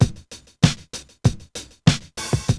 T2_funkdrms.wav